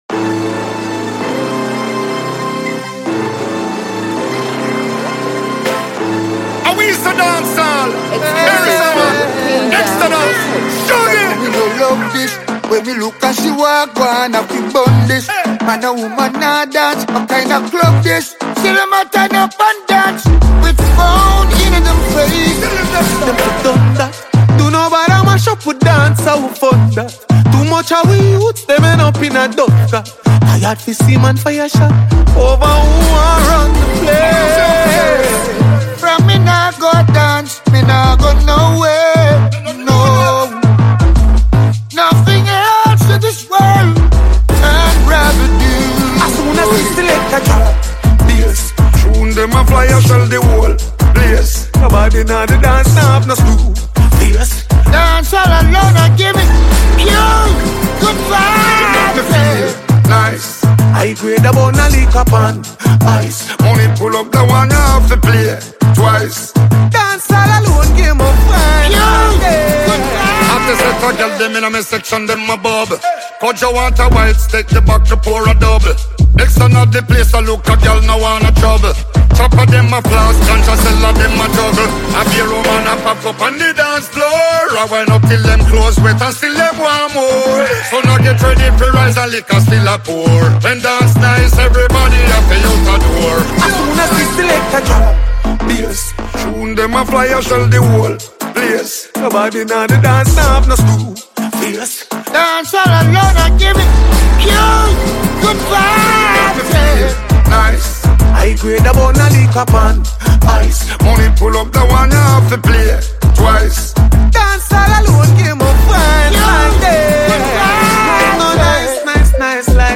blending reggae soul with modern dancehall energy.